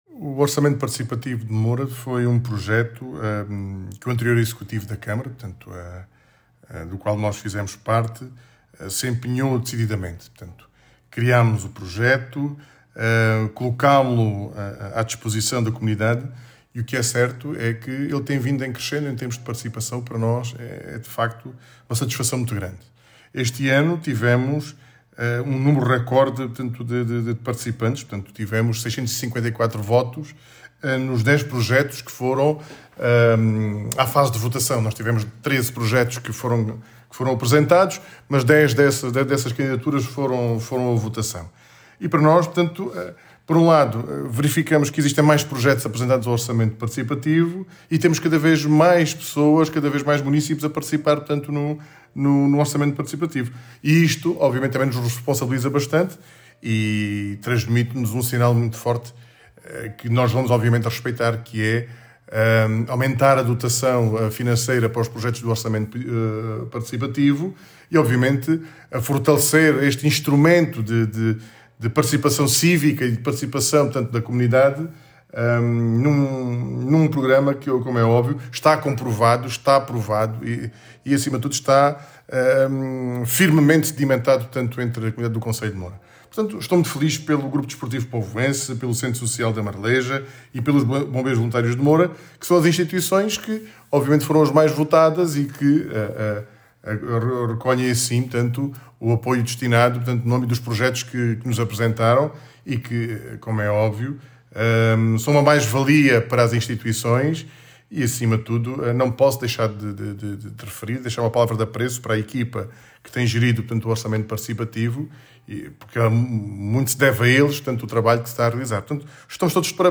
Declaracoes-Presidente-Orcamento-Participativo.mp3